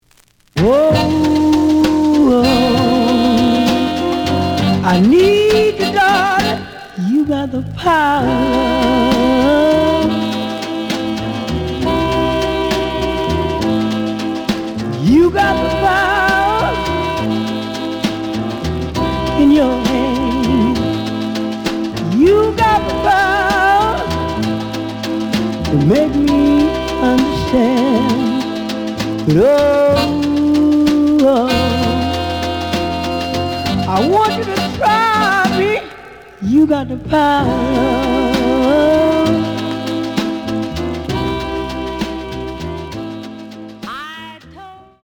The audio sample is recorded from the actual item.
●Genre: Funk, 60's Funk
Slight noise on B side.